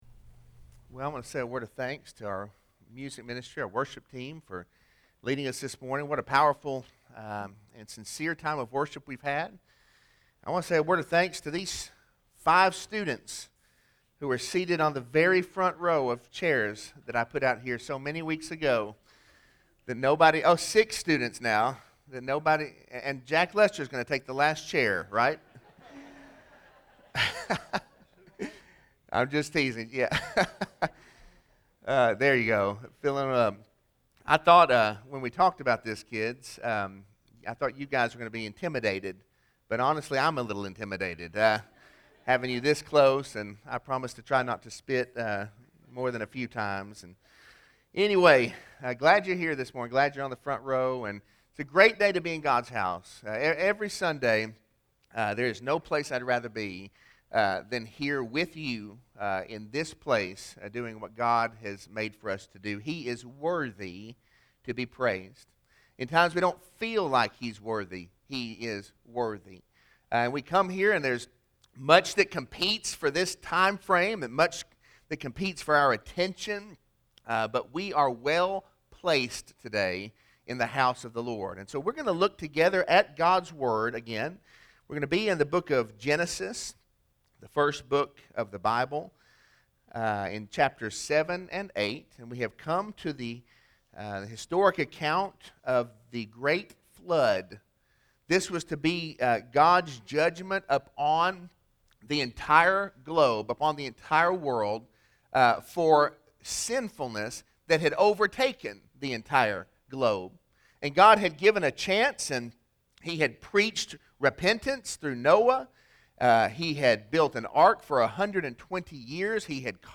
Sermon-5-2-21.mp3